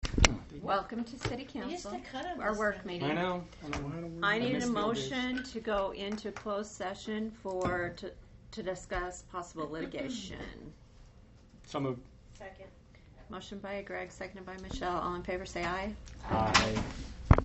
City Council Meeting Agenda